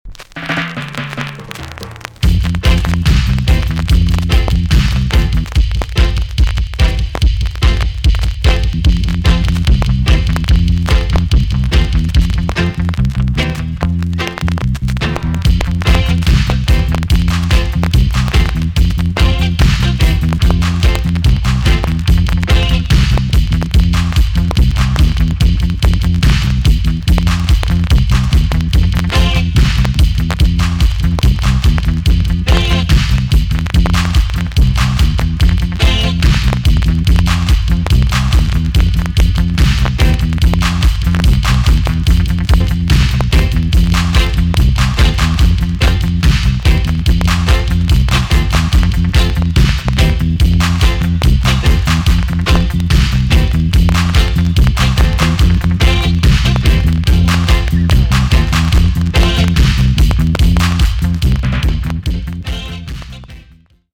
TOP >80'S 90'S DANCEHALL
B.SIDE Version
VG ok 全体的に軽いチリノイズが入ります。